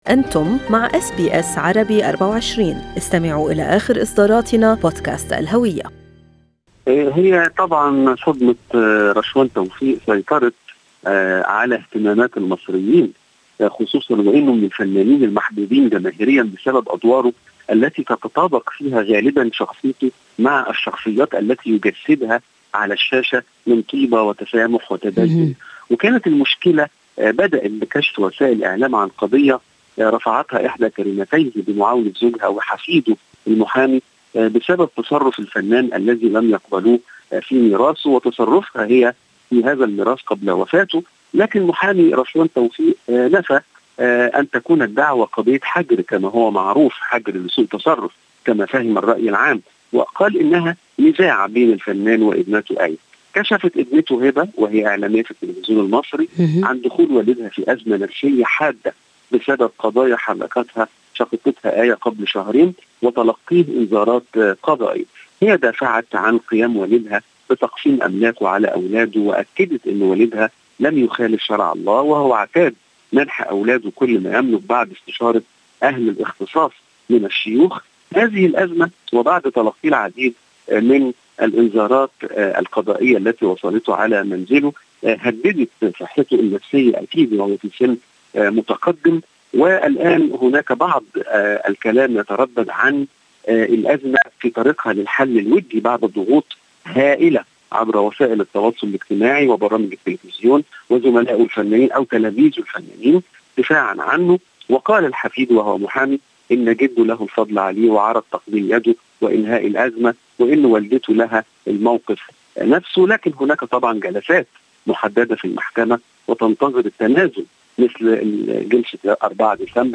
من مراسلينا: أخبار مصر في أسبوع 1/12/2021